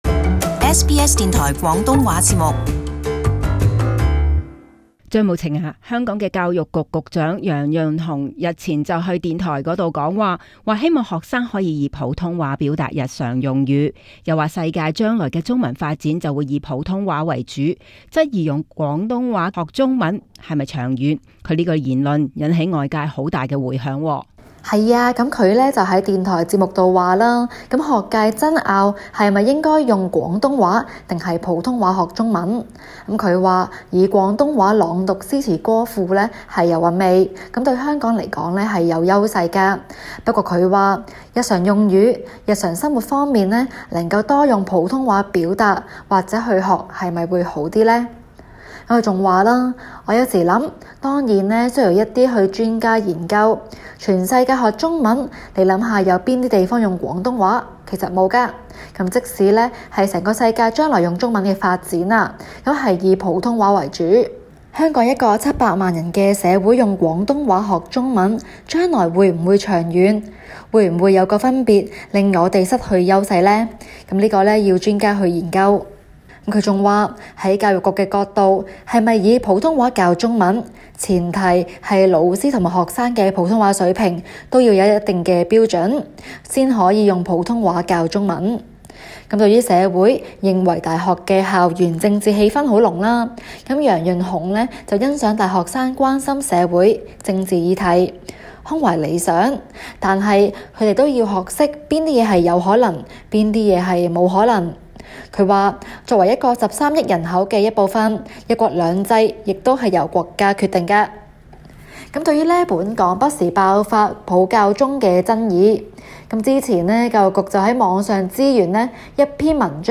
【中港快訊】教育局長言論惹迴響